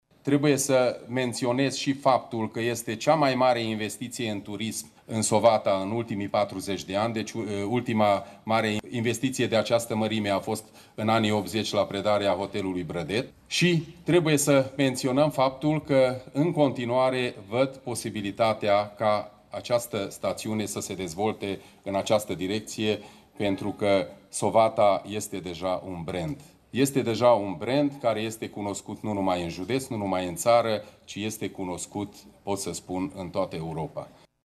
Prezent la ceremonia de inaugurare, președintele CJ Mureș, Péter Ferenc, a declarat că este cea mai mare investiție în turism din ultimele decenii, din staținea Sovata: